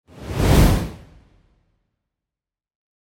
دانلود آهنگ آتش 18 از افکت صوتی طبیعت و محیط
دانلود صدای آتش 18 از ساعد نیوز با لینک مستقیم و کیفیت بالا
جلوه های صوتی